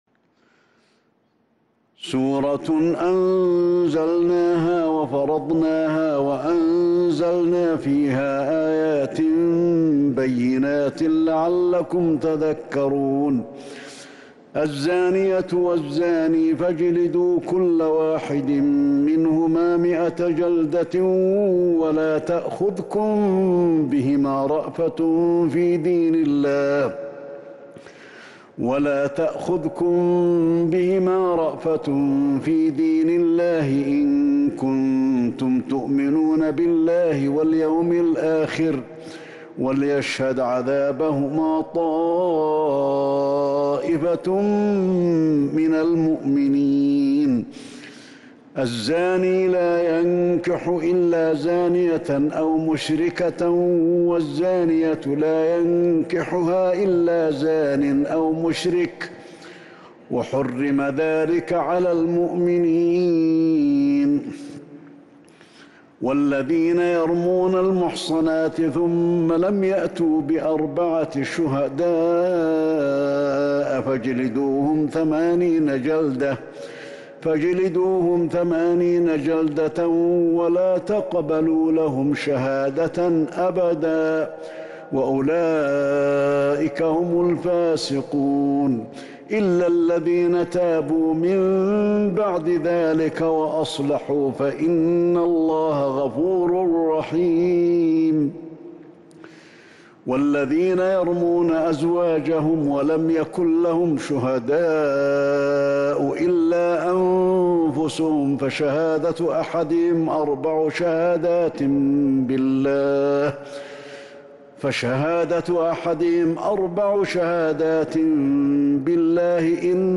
سورة النور كاملة من تراويح الحرم النبوي 1442هـ > مصحف تراويح الحرم النبوي عام 1442هـ > المصحف - تلاوات الحرمين